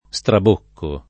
strabocco [ S trab 1 kko ]